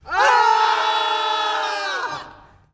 Horror scream group frightened shock scared ext